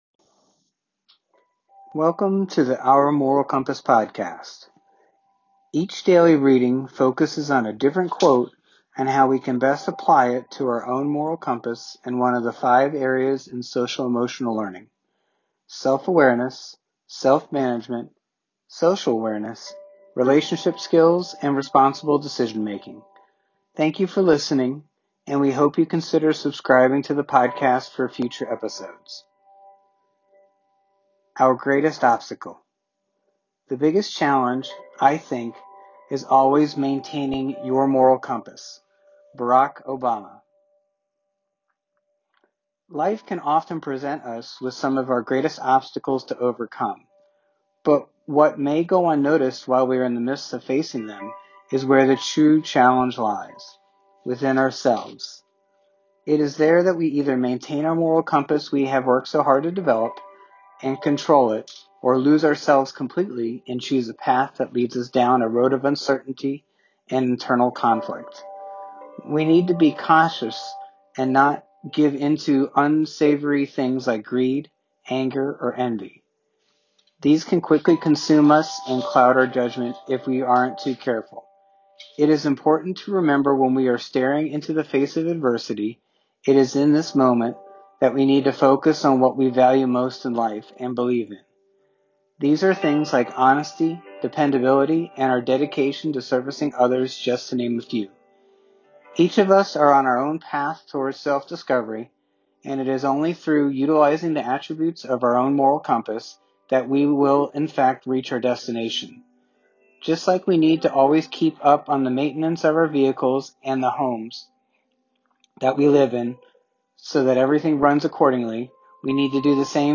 Each daily reading focuses on a different quote on how we can best apply it to our own moral compass and one of the five areas in Social Emotional Learning: Self-Awareness, Self-Management, Social Awareness, Relationship Skills and Responsible Decision Making.